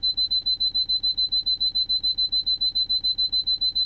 响亮的哔哔声警报
描述：我试图复制某种警报。您也可以将它用作非常讨厌的闹钟。还提供更多混响。
标签： 混响 唤醒 蜂鸣声 早晨 闹钟 闹钟 C锁 蜂鸣器
声道立体声